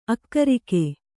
♪ akkarike